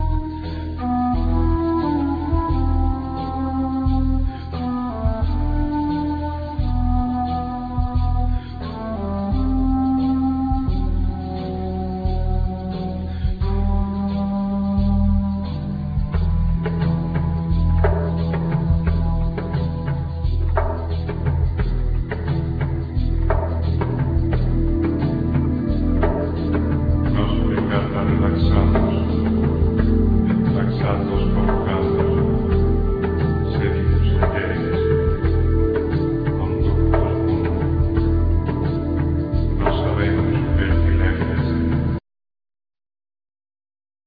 Tar,Riq,Bendir,Shakers,Caxixis,Bodhram
Cajon,Darhuka,Spanish Guitar,Bass Guitar
Viola,Violin